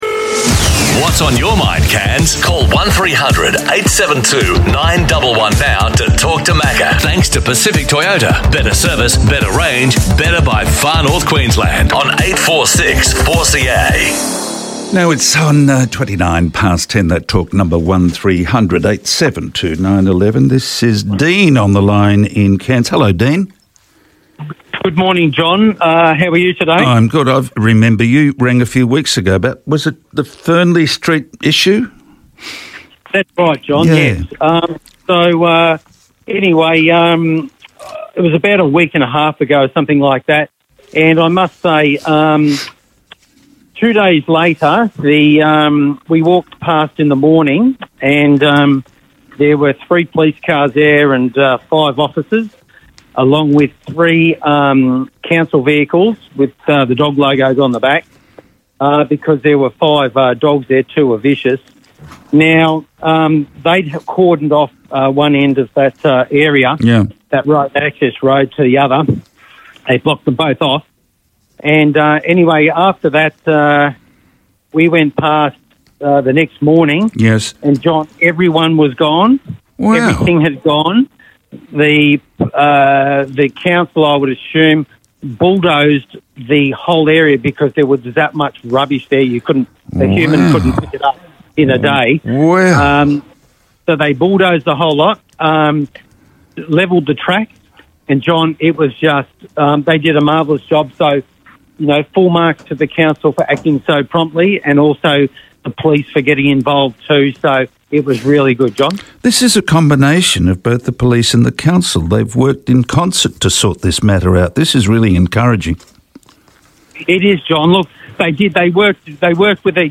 Cairns Regional Council's Mayor Bob Manning happened to be in-studio to comment on the resolution.